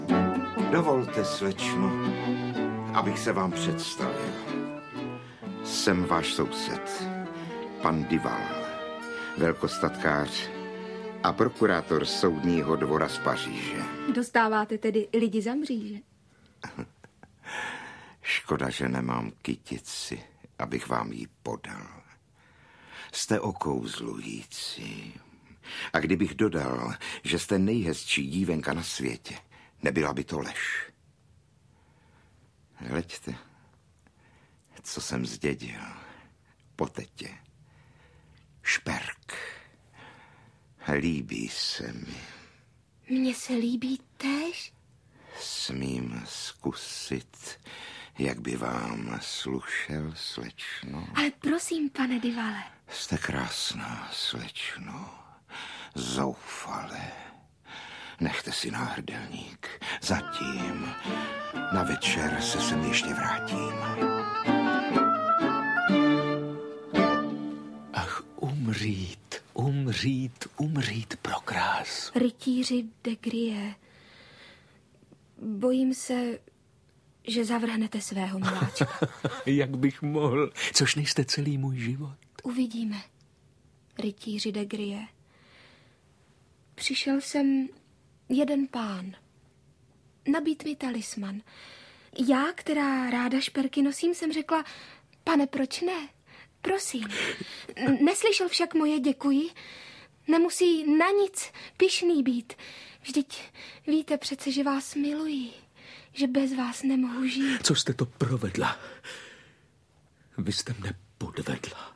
Manon Lescaut audiokniha
Ukázka z knihy
Libuše Šafránková jako Manon je zde zosobněním něhy, téměř dětské lehkomyslnosti, mámení a sladké koketnosti, Viktor Preiss v roli zamilovaného rytíře předvádí závratě milostné vášně, jíž všechno obětuje. Hudba Eduarda Douši, interpretovaná kvartetem Bohuslava Martinů, v souznění s textem umocňuje naši půvabnou nahrávku, která má šanci oslovit mladé posluchače a seznámit je tím nejpříjemnějším způsobem s vrcholy české poezie a literatury.